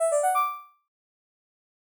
trophy.wav